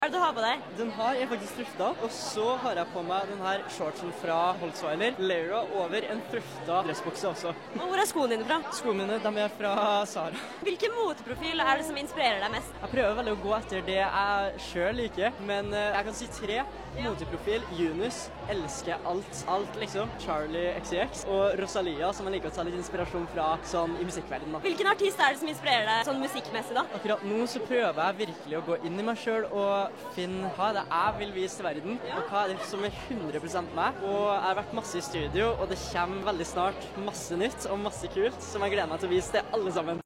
✨Interview with Kyle Alessandro ✨